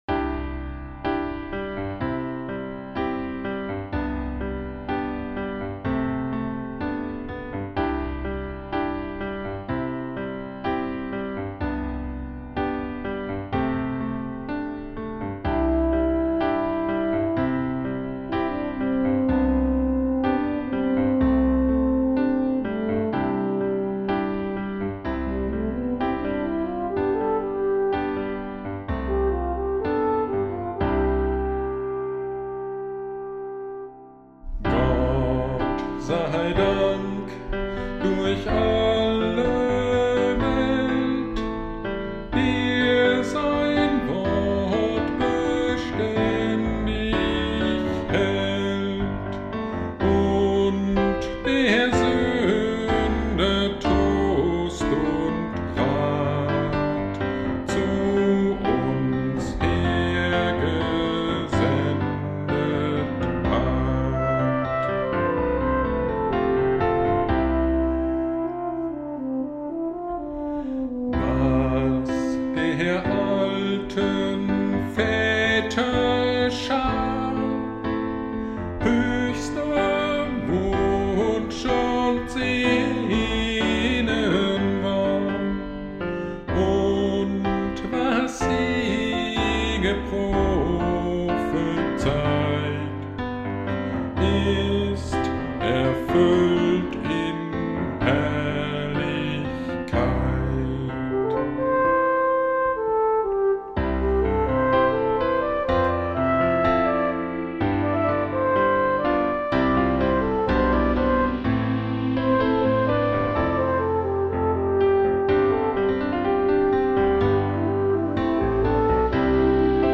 GD Heiligabend 2022 – Predigt zu Johannes 6.35 und Kolosser 2.3+9